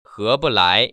[hé ‧bu lái] 허부라이